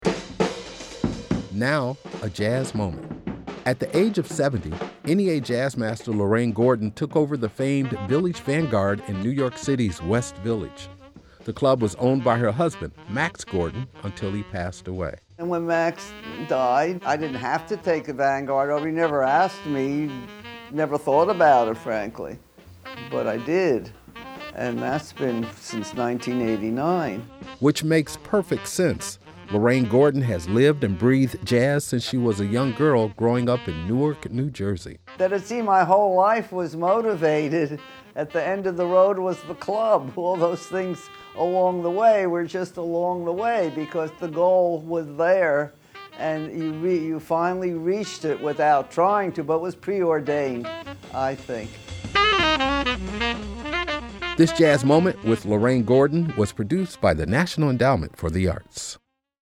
MUSIC CREDIT: Excerpt of "Four" composed by Miles Davis and performed by Sonny Rollins from A Night at the Village Vanguard, used by permission of EMI Capitol and used by permission of Concord Music Group (BMI).